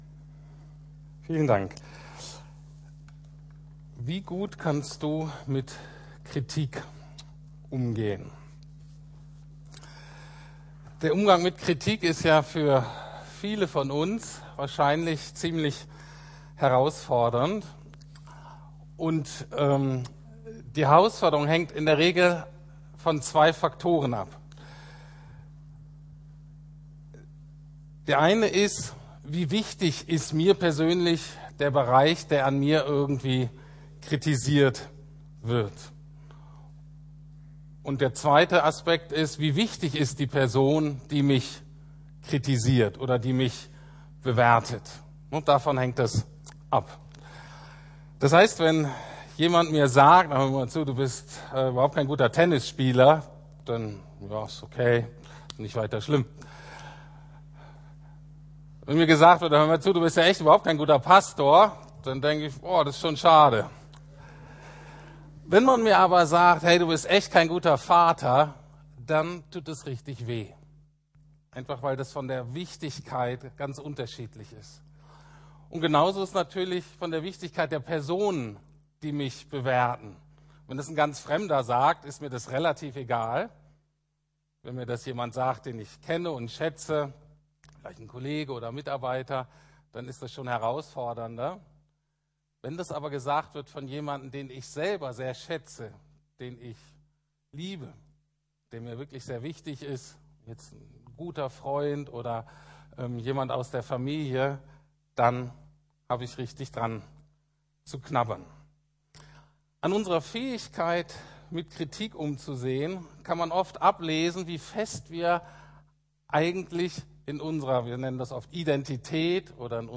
Gemeinsam wachsen in Gnade und Wahrheit Teil 2 ~ Predigten der LUKAS GEMEINDE Podcast